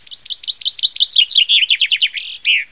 Ein munterer Besucher auf dem Balkon ist ein Buchfink, kugelrund, mit einem hübschen braunroten Brüstlein. Er sitzt genüsslich auf der Balkonbrüstung und zwitschert , der kleine gelbe Schnabel öffnet und schliesst sich, so dass man seinen Gesang «vom Schnabel ablesen» kann.
Klicken Sie auf das Bild, und Sie hören sein Lied!